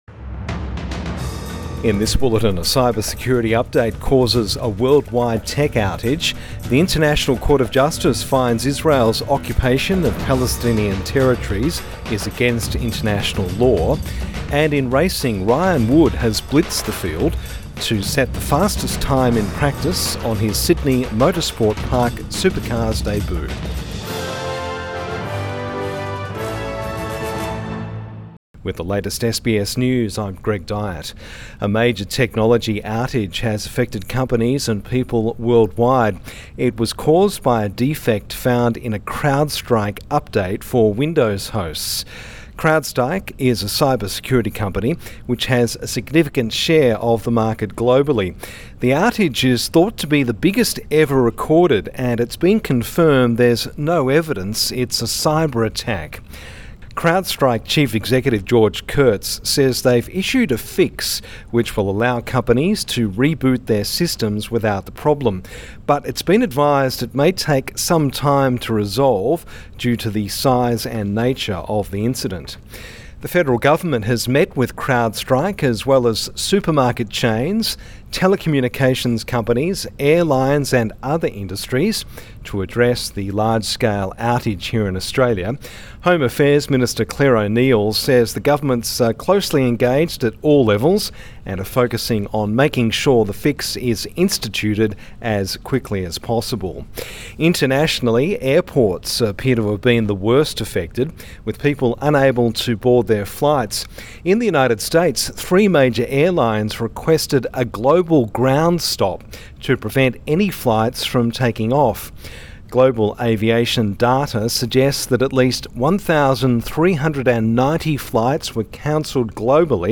Morning News Bulletin 20 July 2024